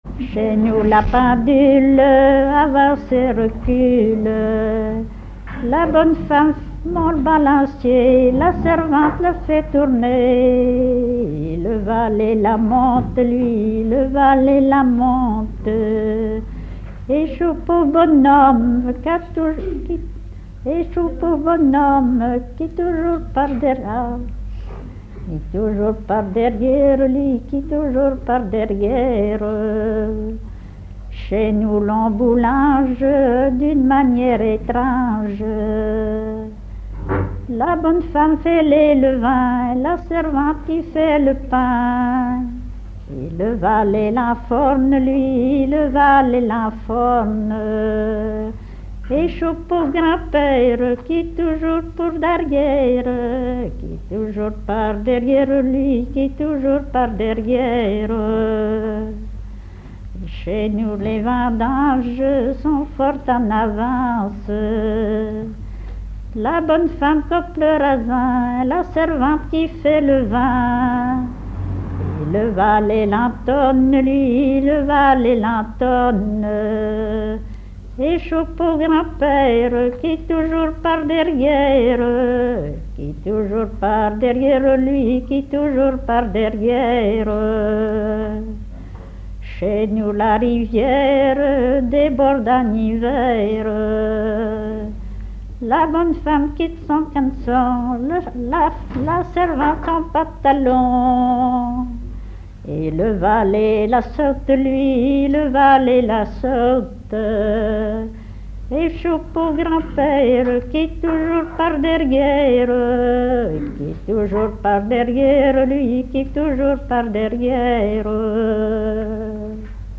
danse : ronde : grand'danse
Genre énumérative
à la salle d'Orouët
Pièce musicale inédite